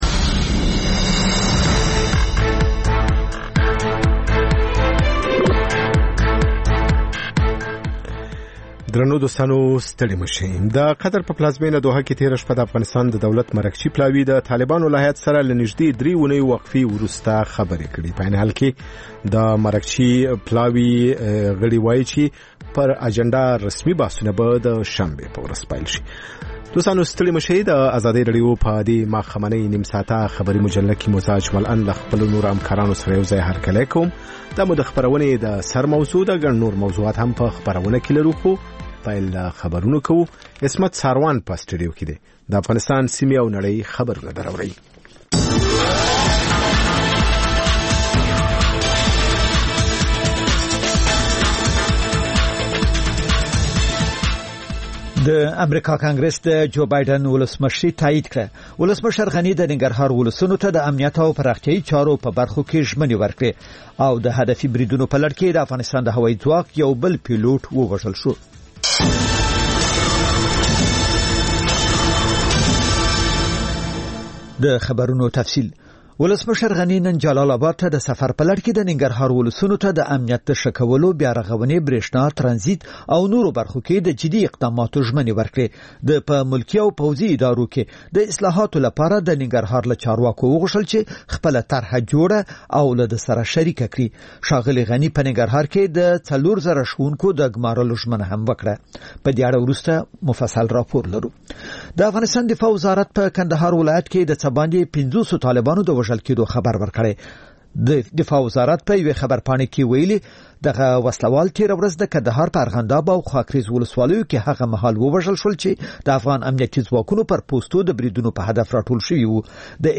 ماښامنۍ خبري مجله